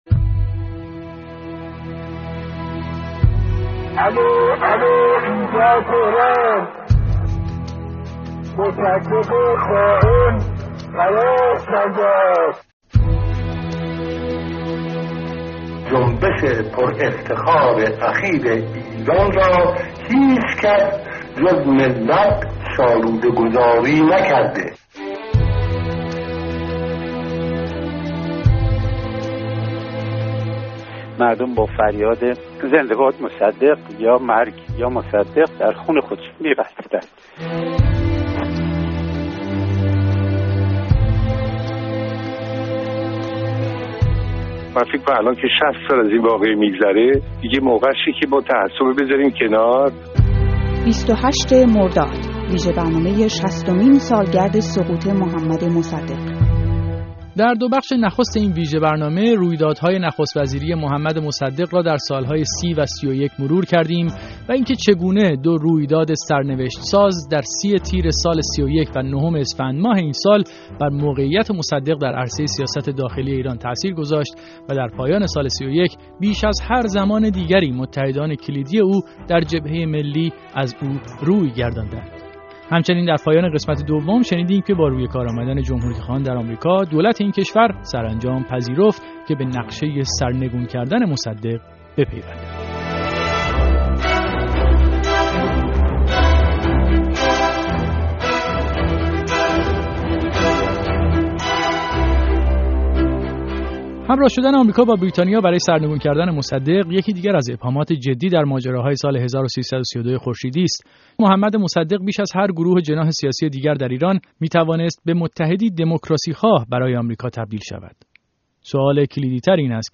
نفع آمریکا در برکناری مصدق، اختلافات مصدق با کاشانی و بقایی و انحلال مجلس هفدهم، هم‌داستان شدن آمریکا و بریتانیا در برکناری مصدق و همراهی فضل‌الله زاهدی با این طرح، و اینکه طرح اولیه برکناری چه بود و چطور به کودتای نظامی بدل شد، از موضوعاتی است در سومین بخش از مستند رادیویی شصتمین سالگرد سقوط مصدق به آن پرداخته شده است.